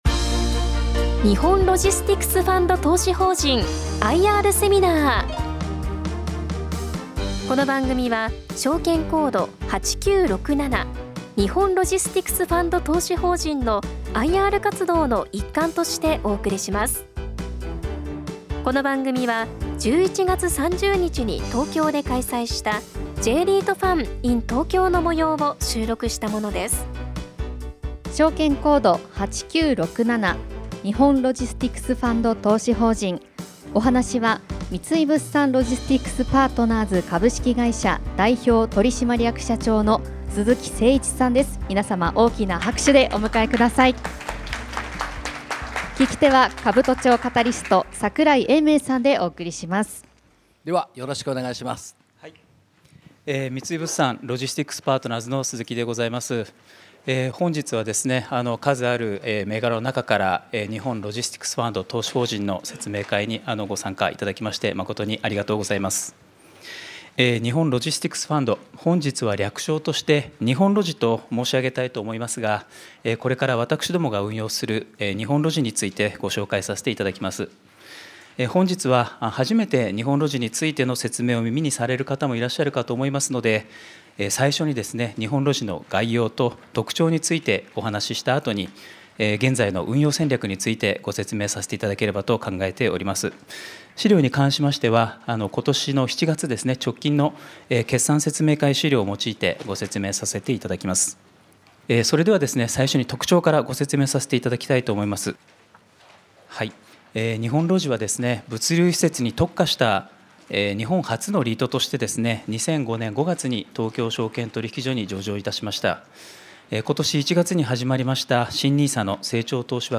この番組は2024年11月30日に東京で開催した「J-REITファン」の模様をダイジェストでお送りいたします。